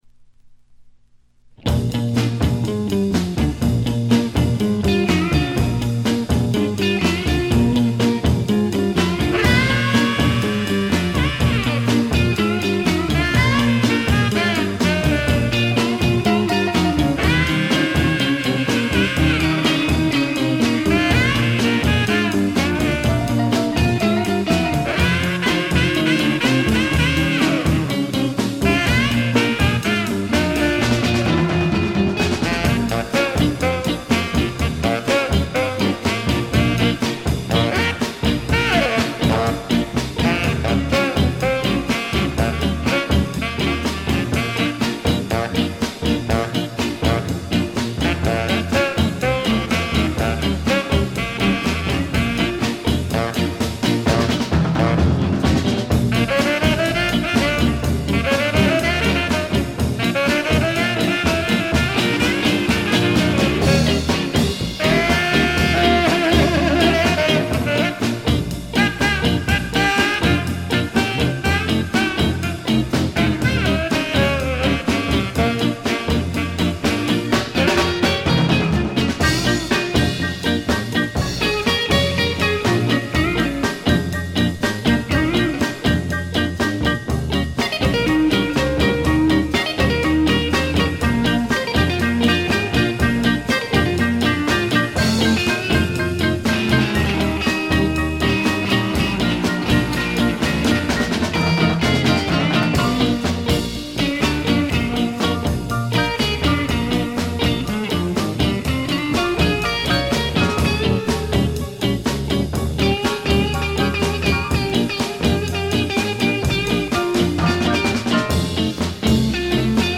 わずかなノイズ感のみ。
ぶりぶりで楽しいインスト集。
試聴曲は現品からの取り込み音源です。